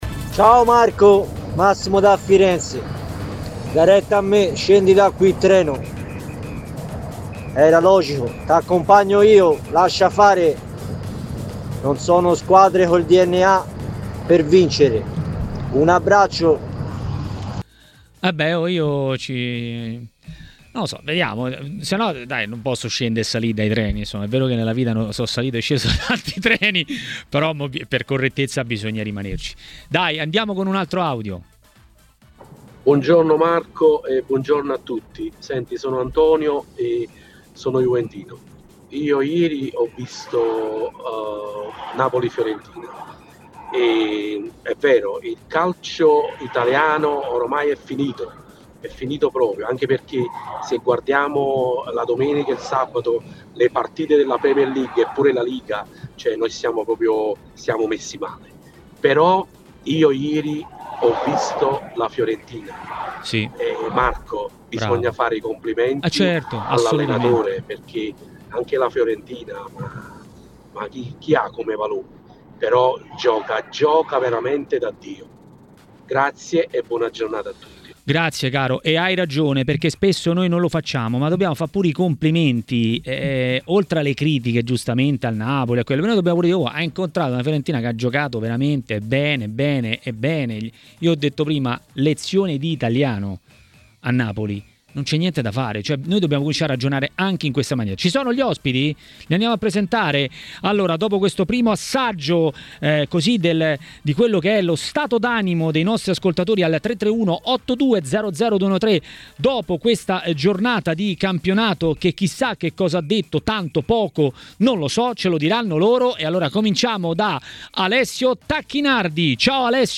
Ospiti: Alessio Tacchinardi